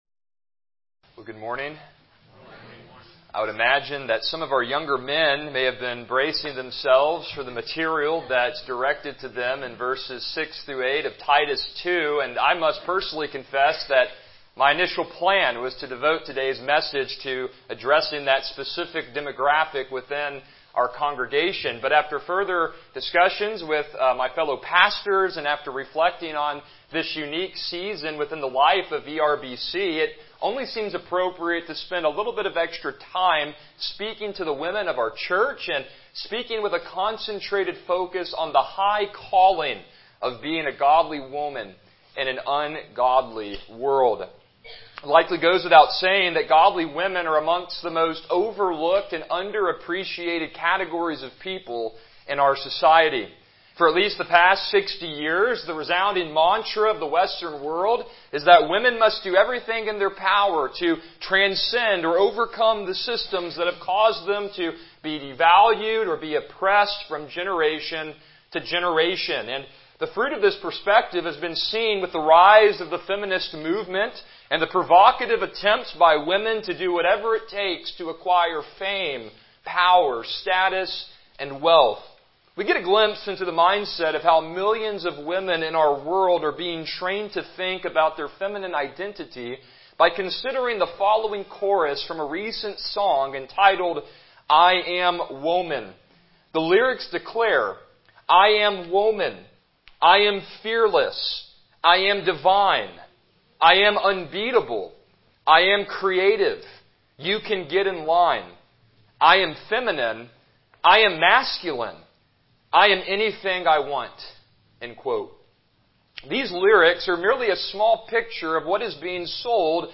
Passage: Proverbs 31:10-31 Service Type: Morning Worship